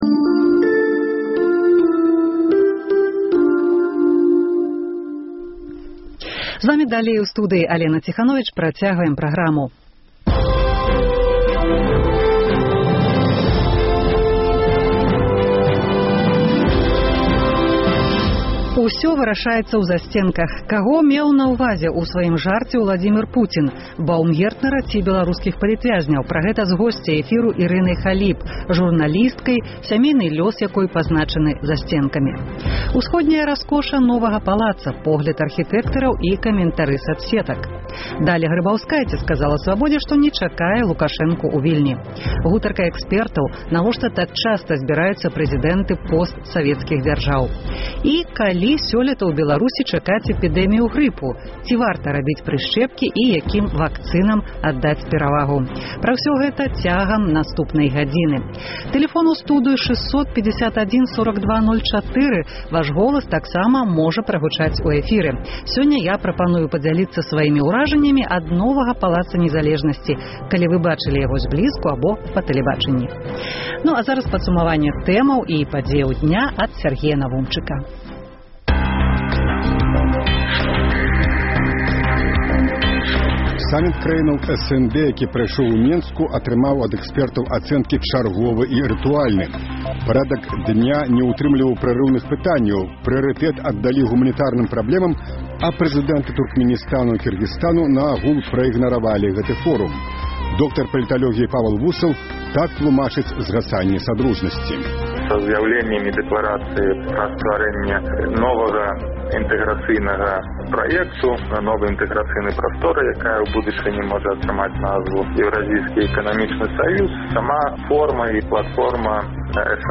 Каго меў на ўвазе Уладзімір Пуцін: Баўмгертнера ці беларускіх палітвязьняў? Пра гэта – з госьцяй эфіру Ірынай Халіп, журналісткай, сямейны лёс якой пазначаны засьценкамі.
Даля Грыбаўскайце сказала Свабодзе, што не чакае Лукашэнку ў Вільні. Гутарка экспэртаў: навошта так часта зьбіраюцца прэзыдэнты постсавецкіх дзяржаў?